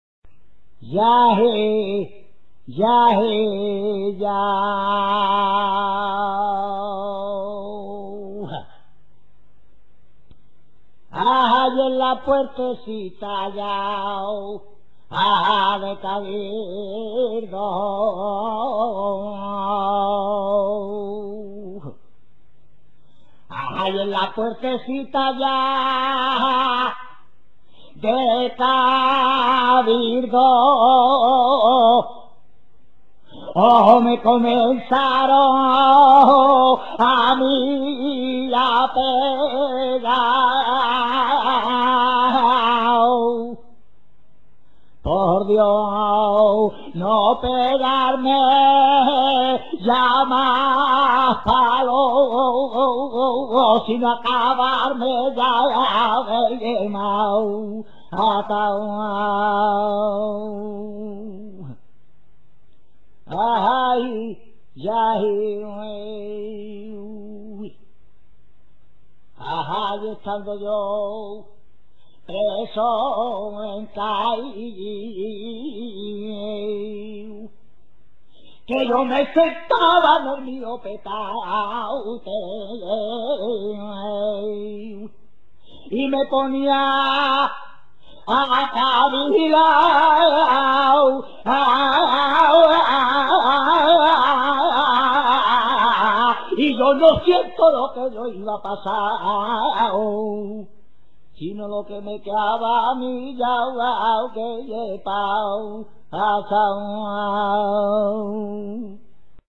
carcelera.mp3